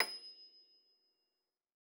53j-pno27-D6.wav